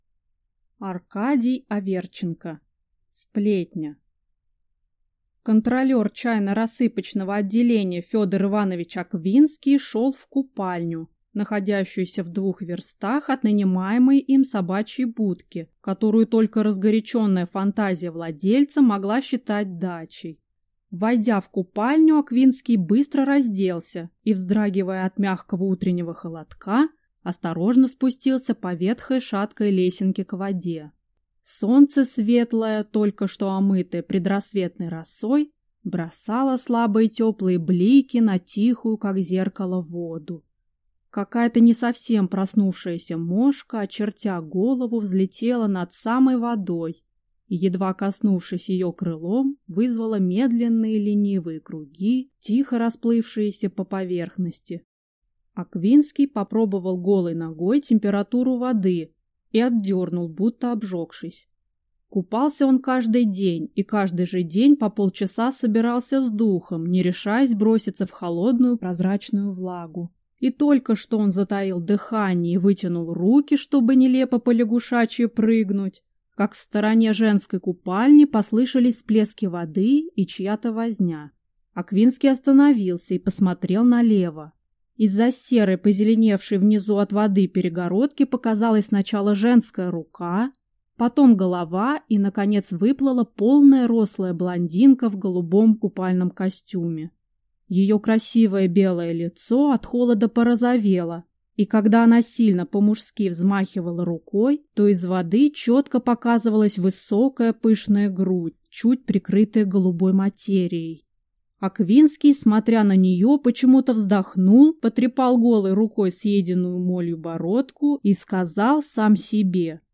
Аудиокнига Сплетня | Библиотека аудиокниг
Прослушать и бесплатно скачать фрагмент аудиокниги